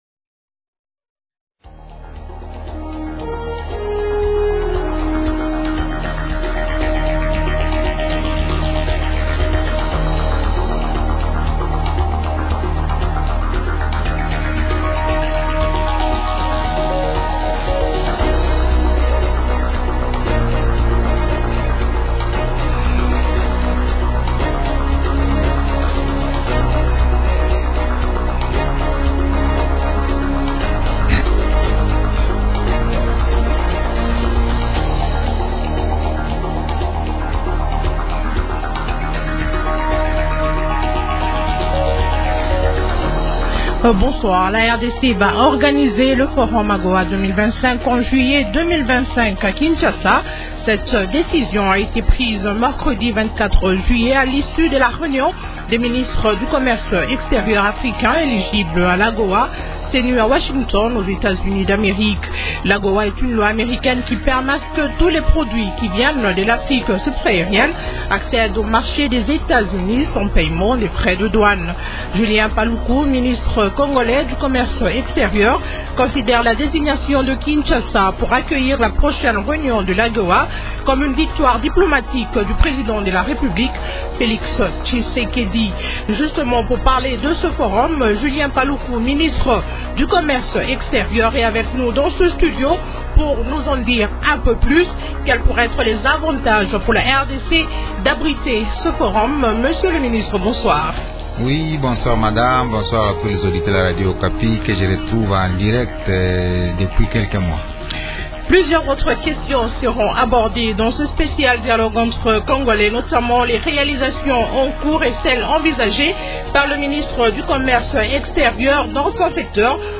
Dialogue entre congolais de ce mercredi 31 juillet reçoit en invité spécial Julien Paluku, ministre du Commerce extérieur.